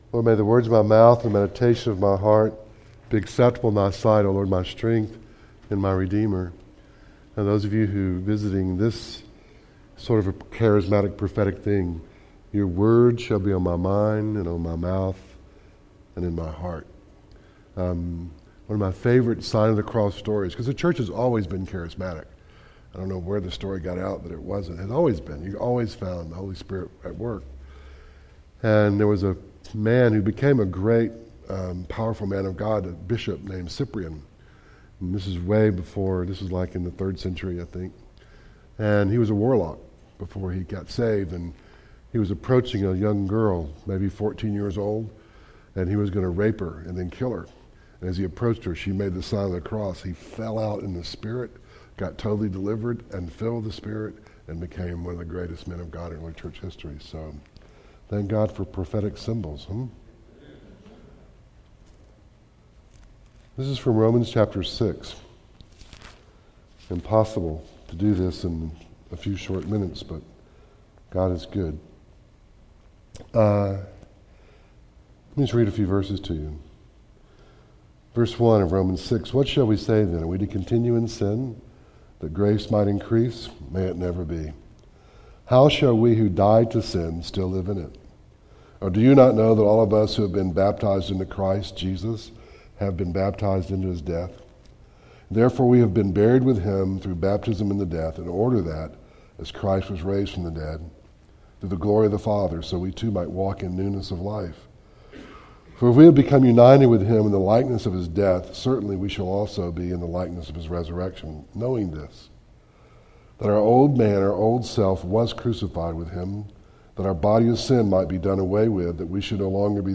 Acts & Mark Service Type: Wednesday Night